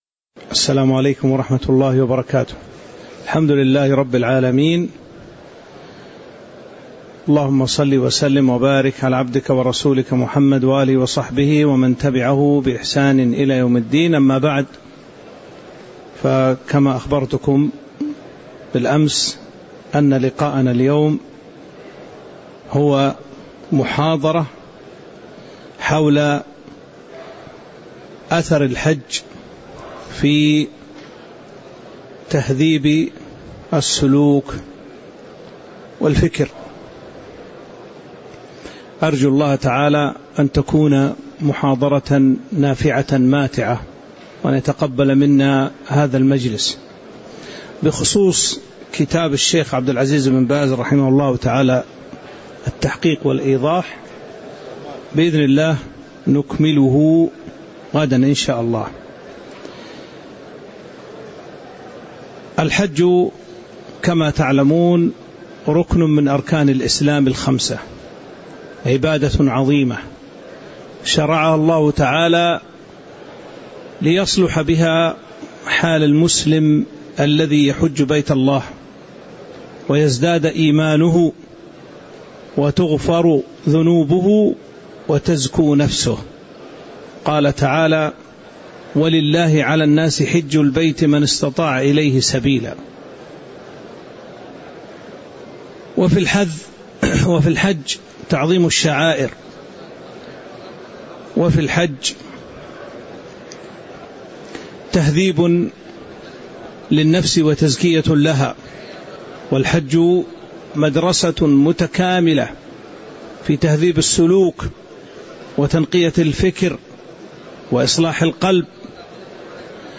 تاريخ النشر ٢٩ ذو القعدة ١٤٤٦ هـ المكان: المسجد النبوي الشيخ